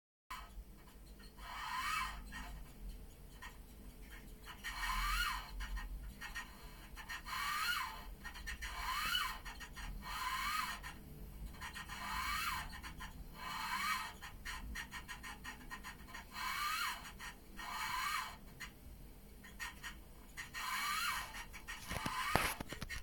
Mon chauffe eau installé depuis moins d'un an, fait du bruit lors des phases de chauffe de l'eau.
Le son vient vraiment de l'intérieur du chauffe eau.
C'est à cela qu'on pouvait penser à l'écoute du premier bruit.